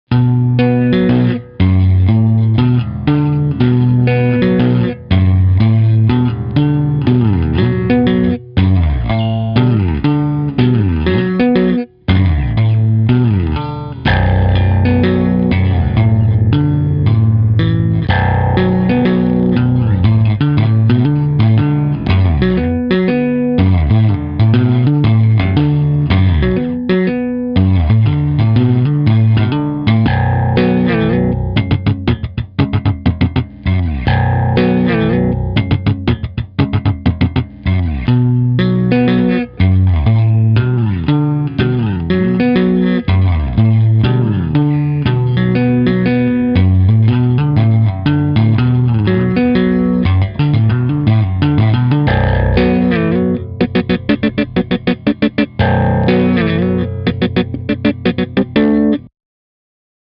Je to pořízené přes iPad v GarageBandu přes Clean Combo.
Tappingová variace